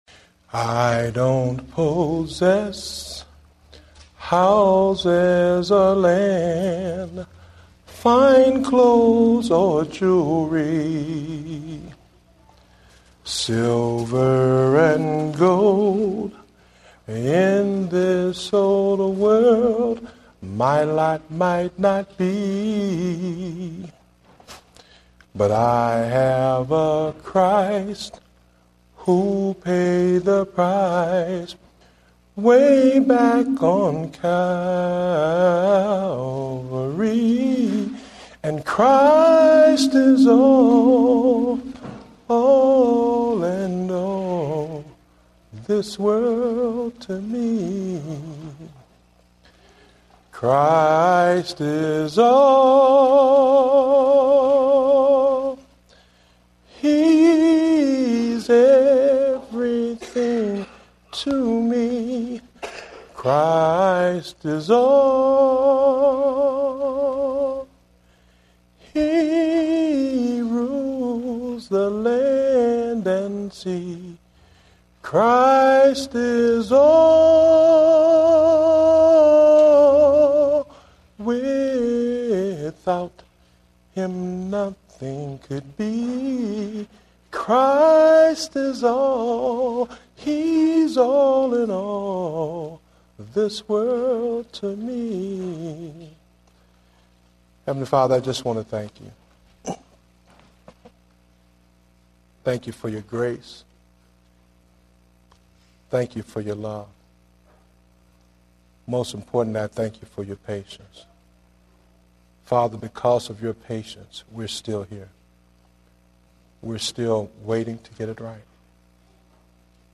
Date: November 14, 2010 (Morning Service)